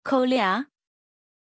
Pronunciation of Kolya
pronunciation-kolya-zh.mp3